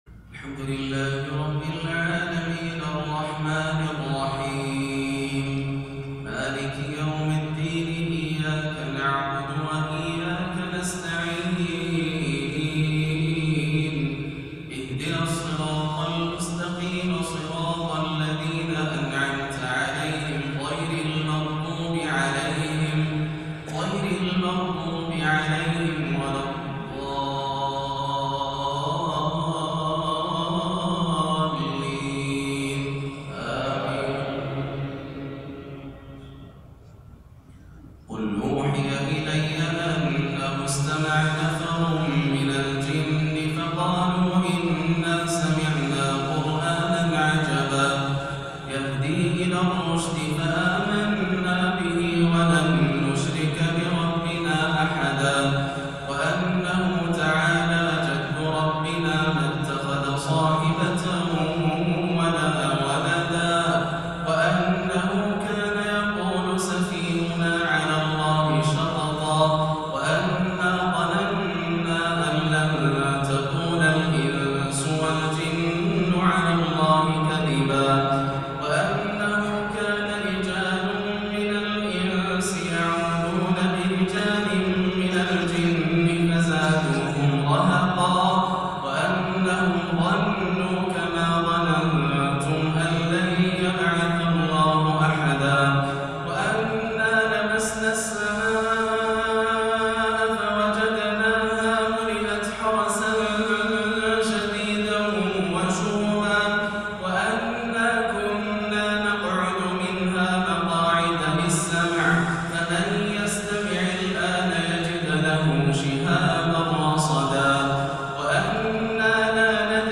عشاء الأثنين 5-1-1439هـ سورتي الجن و المزمل > عام 1439 > الفروض - تلاوات ياسر الدوسري